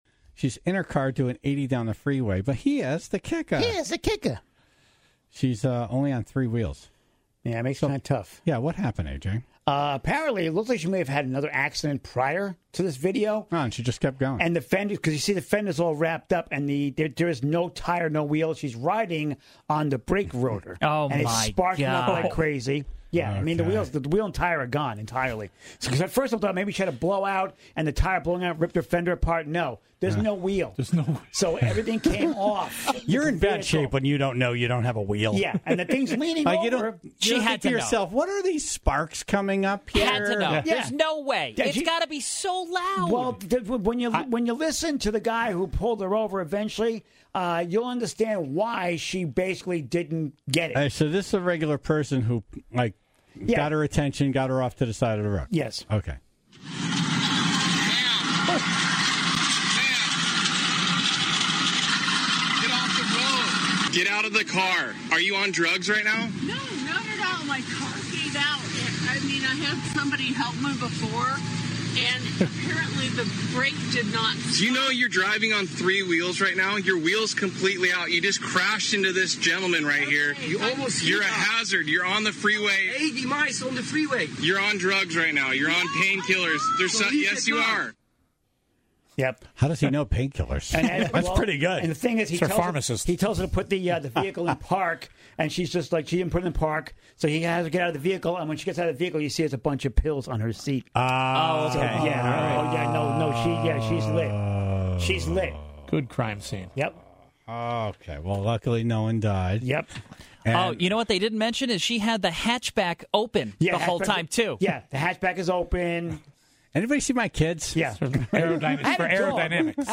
as the Tribe called in their stories of the craziest things they seen while driving. Bathroom breaks, upside down vehicles, and marching band stomping through the scene of an accident.